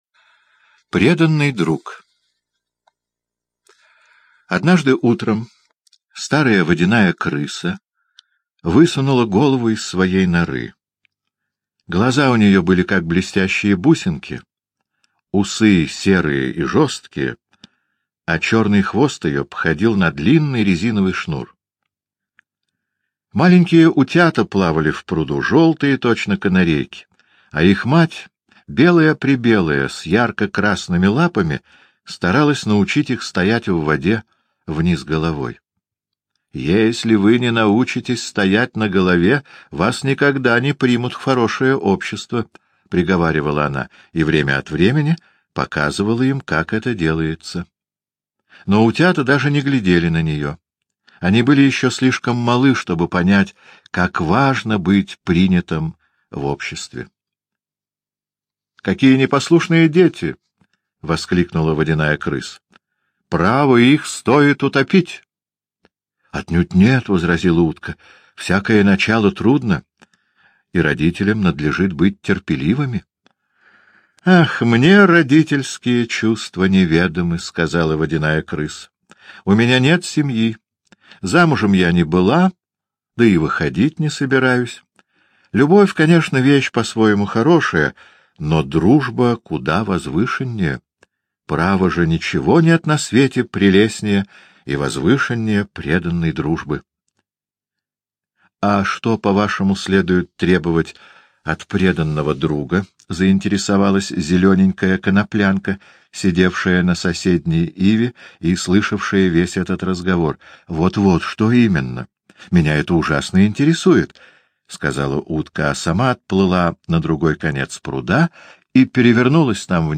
Преданный друг — аудиосказка Уайльда О. История о дружбе трудолюбивого и доброго садовника Ганса и богатого мельника Гью.